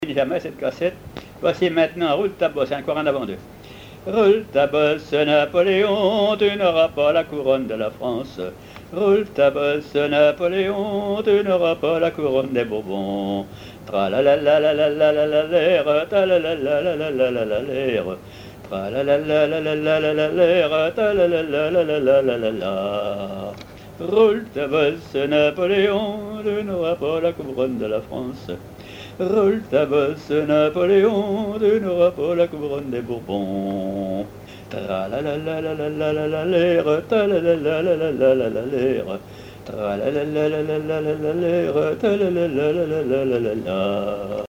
Mémoires et Patrimoines vivants - RaddO est une base de données d'archives iconographiques et sonores.
Couplets à danser
chansons à ripouner ou à répondre
Pièce musicale inédite